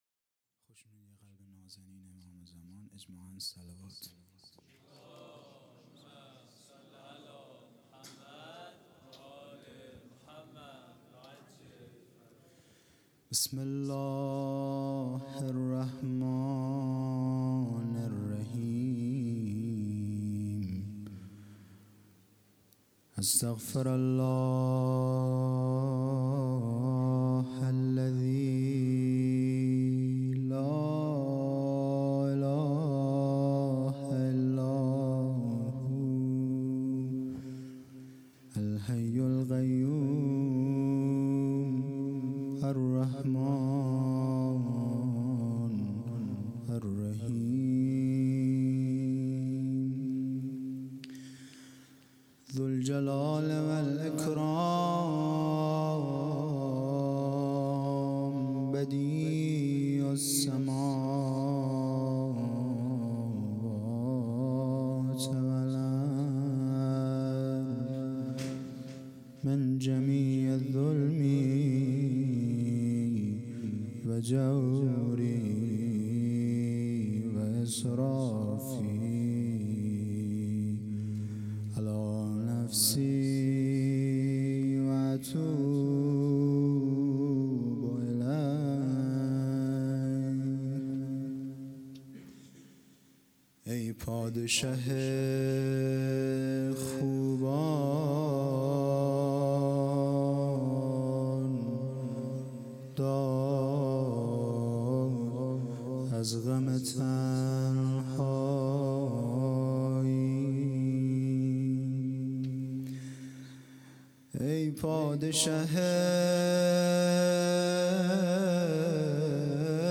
هیئت دانشجویی فاطمیون دانشگاه یزد